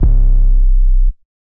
808 [Nintendo].wav